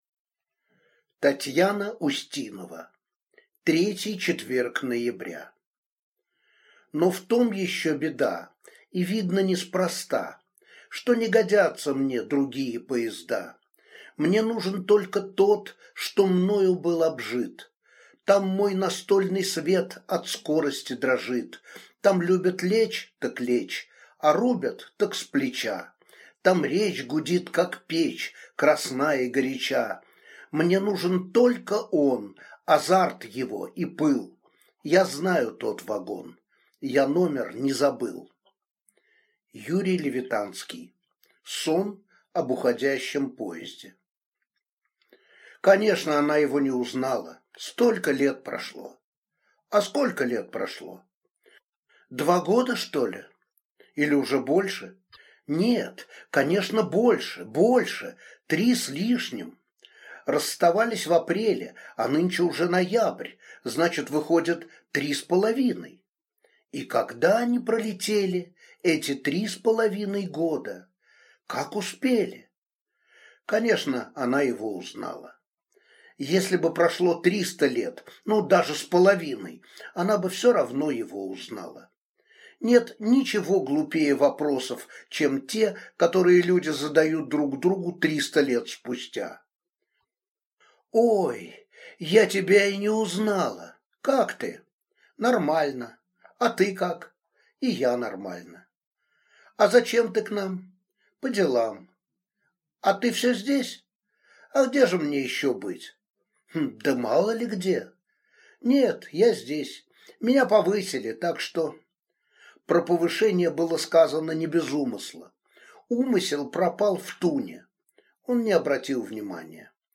Аудиокнига Третий четверг ноября | Библиотека аудиокниг